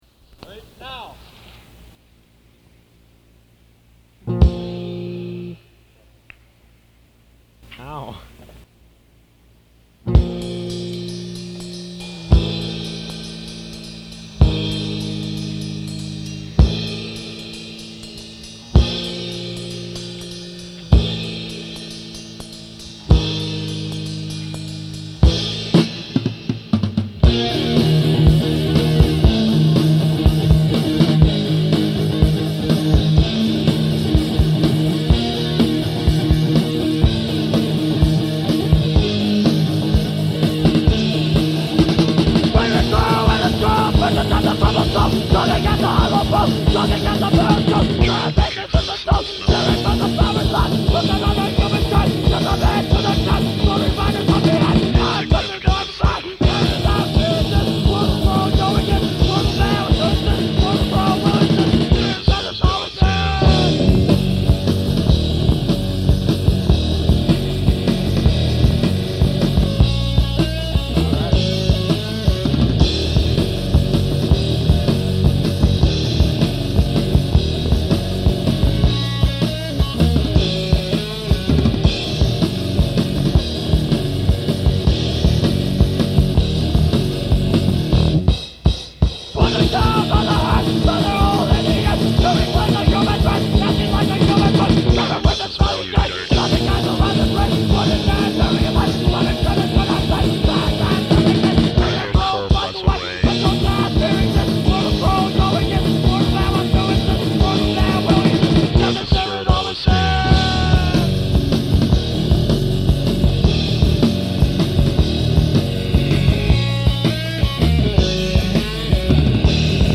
Bass/Vocals
Drums/Vocals
Guitar/Vocals
Punk Tags